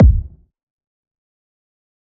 Kicks
Rack Kick4.wav